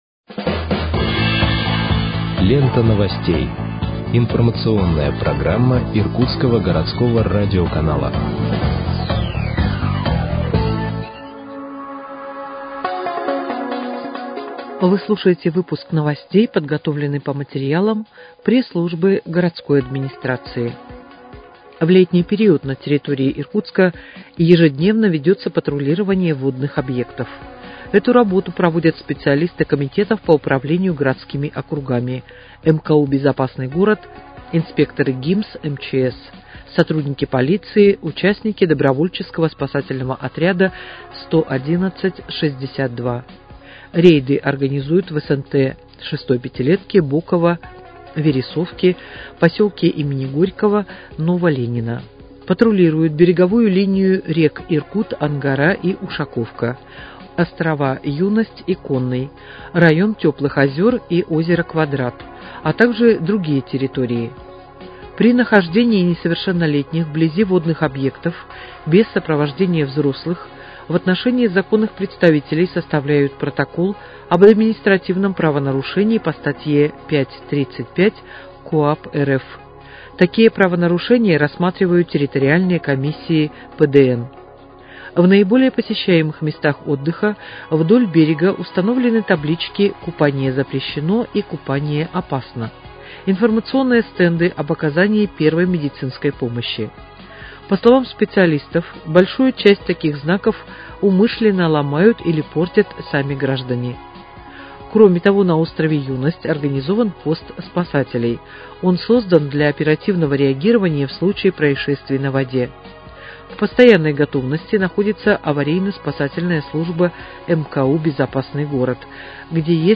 Выпуск новостей в подкастах газеты «Иркутск» от 1.08.2025 № 2